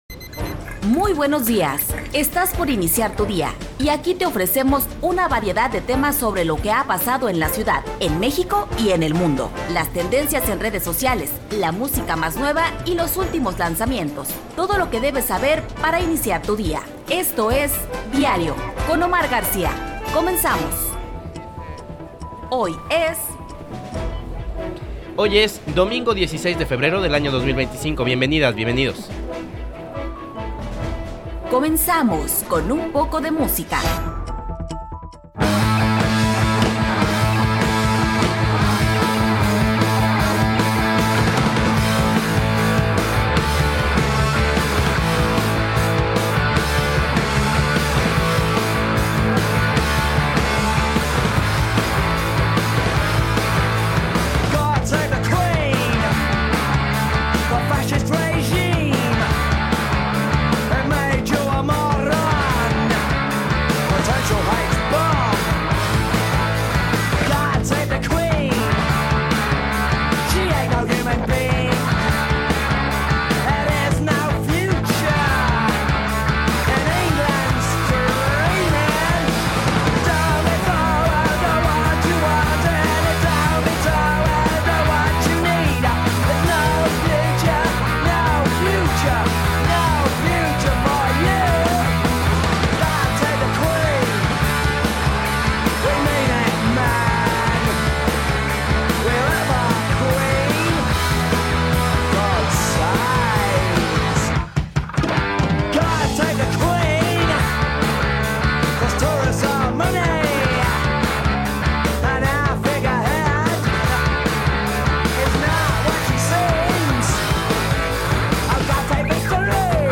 En la entrevista: